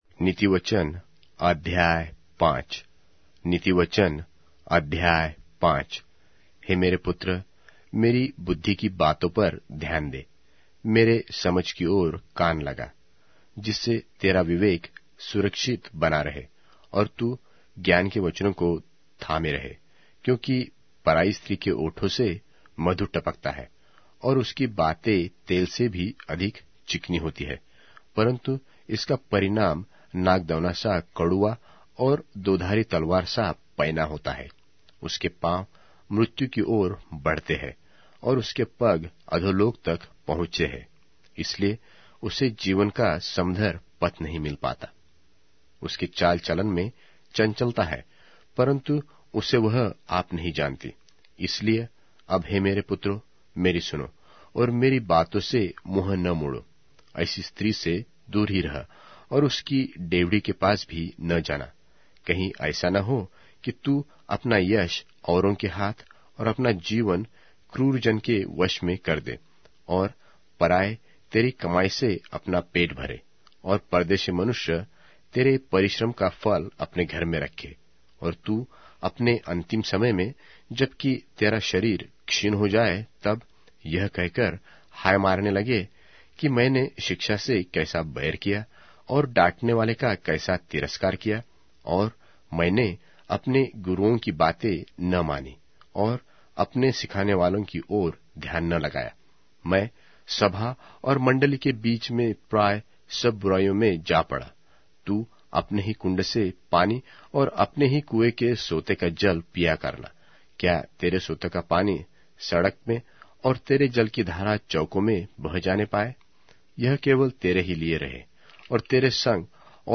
Hindi Audio Bible - Proverbs 5 in Kjv bible version